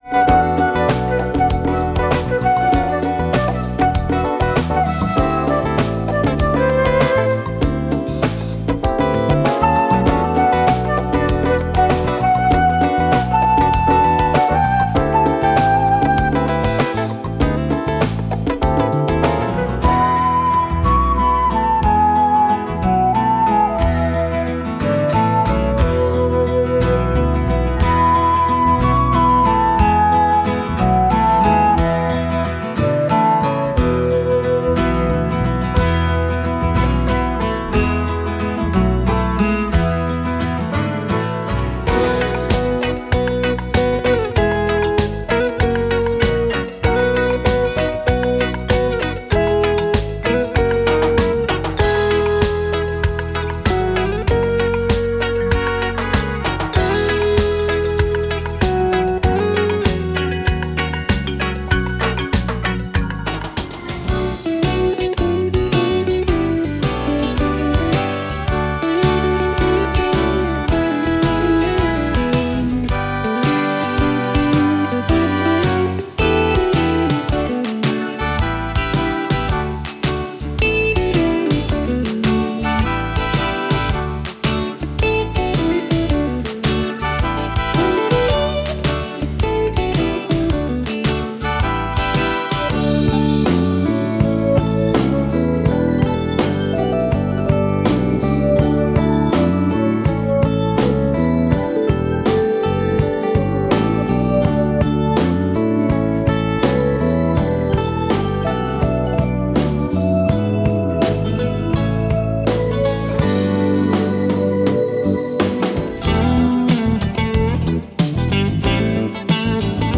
Description: Light Instrumentals and Pop Covers